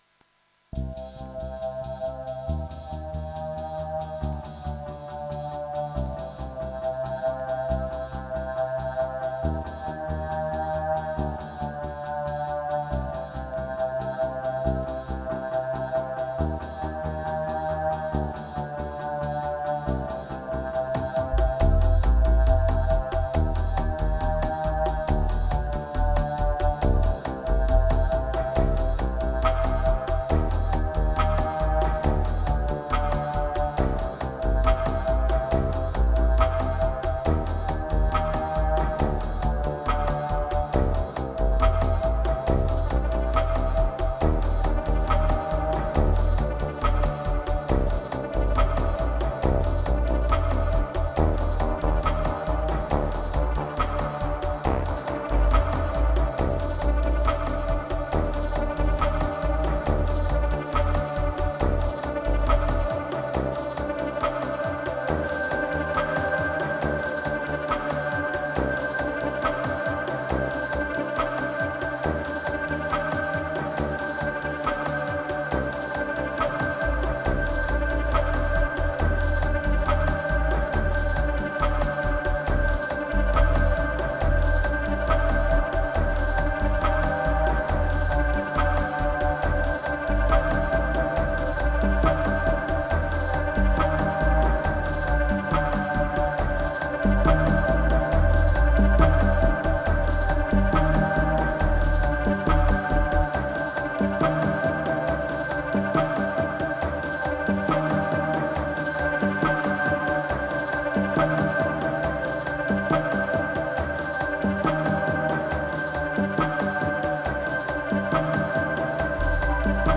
Sweaty spring session. Dark moods, again.